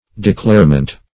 Declarement \De*clare"ment\, n.